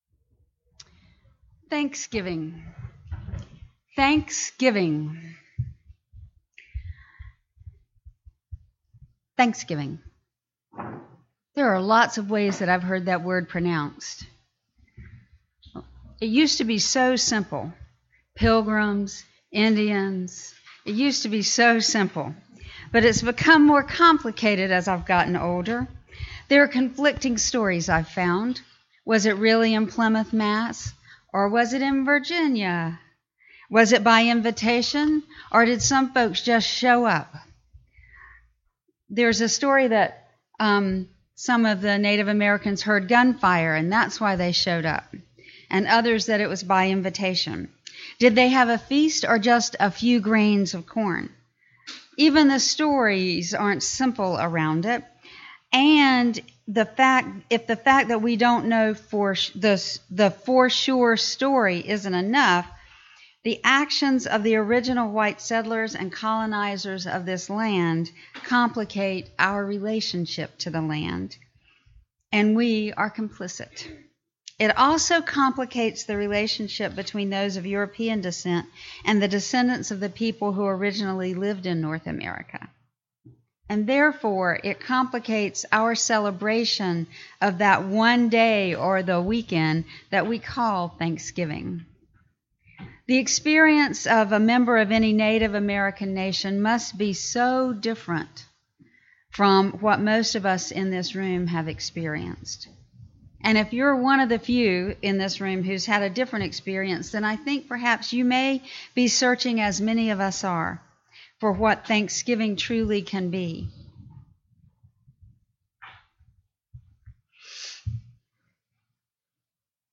This sermon explores the Haudenosaunee Thanksgiving Address, a traditional practice that shifts the focus from a singular historical holiday to a daily lifestyle of gratitude.
The sermon contrasts the complicated, often conflicting narratives of the American Thanksgiving holiday with an indigenous perspective that honors the natural world’s interdependence. Through a series of rhythmic acknowledgments, the speaker invites the audience to offer thanks to Mother Earth, the waters, animal life, and celestial bodies.